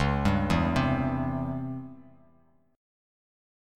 DbmM7b5 chord